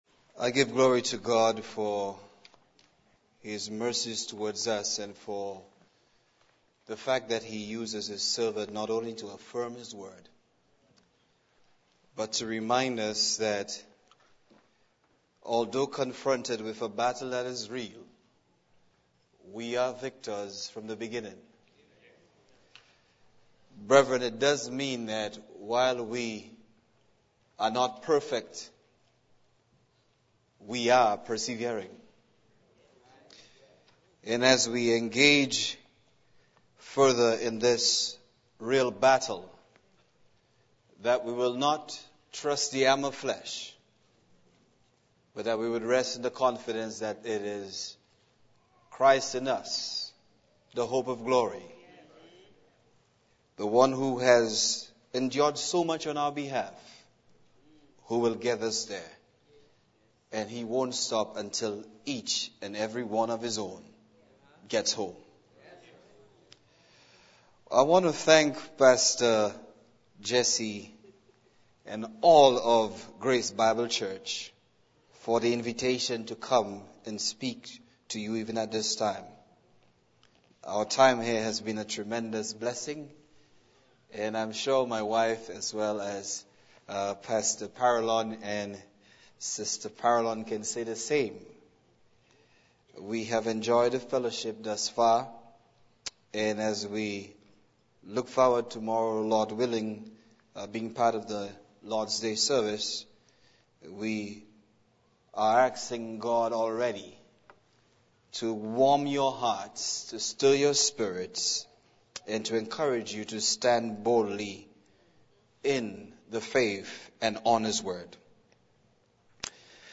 2014 FALL GBC Preaching Conference